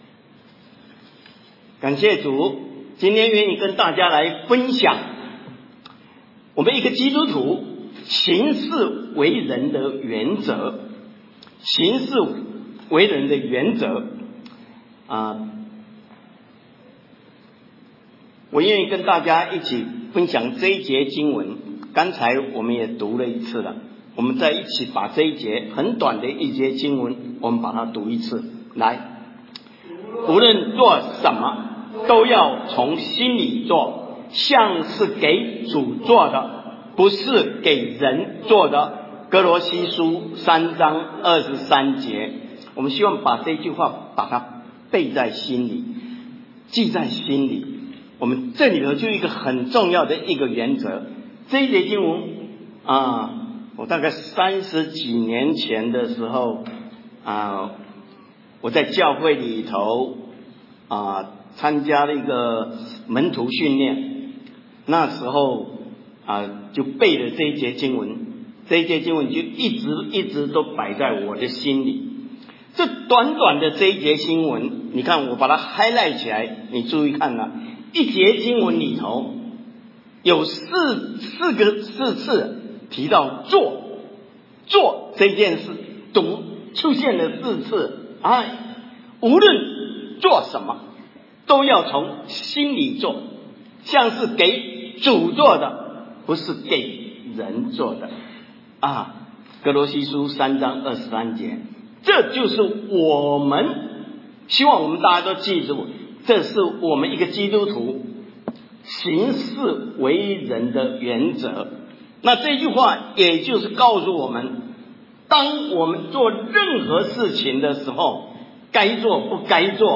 行事为人的原则-讲道.mp3